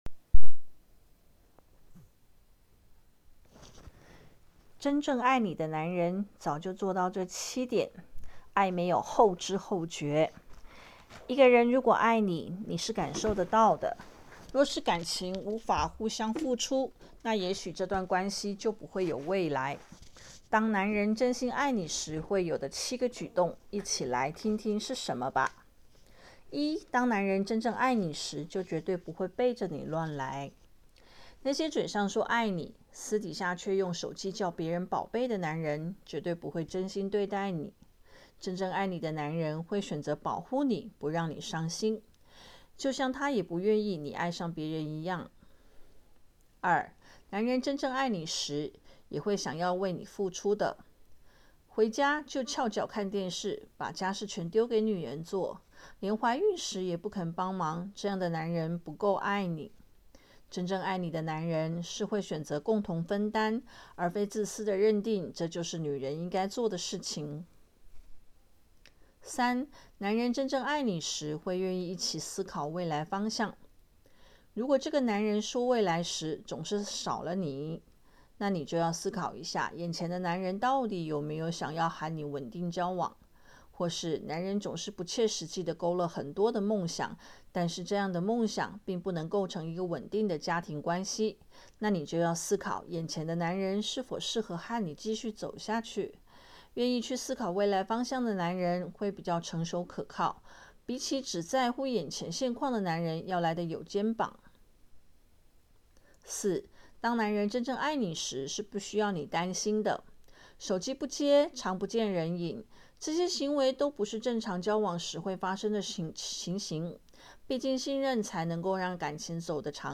聽我「說說」：「愛沒有後知後覺」—語音分享
回到學校宿舍，今晚室友不在，可以容許我自言自語，哈！於是錄了幾段語音。